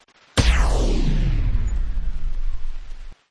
Tesla Lock Sound Scifi – Power Down 1
(This is a lofi preview version. The downloadable version will be in full quality)
JM_Tesla_Lock-Sound_Scifi-Power-Down-1_Watermark.mp3